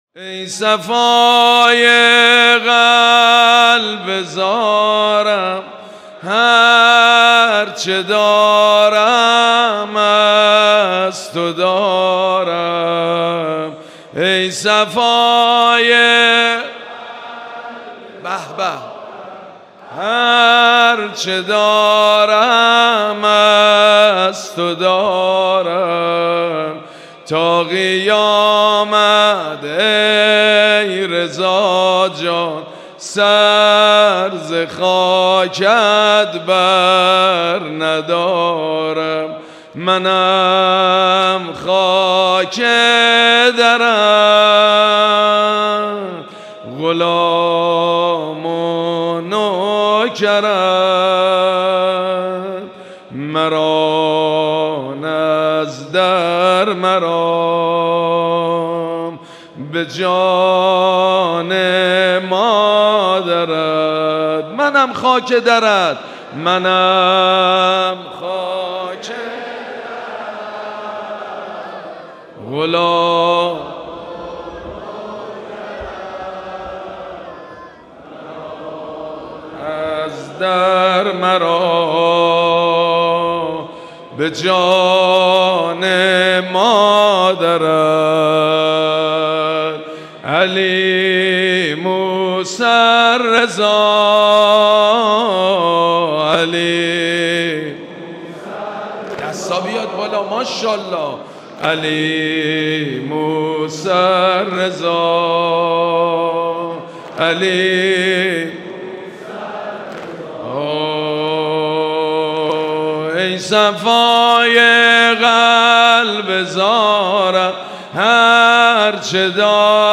مراسم عزاداری ظهر سی‌ام ماه صفر
حسینیه امام خمینی (ره)
زمزمه
حاج سید مجید بنی فاطمه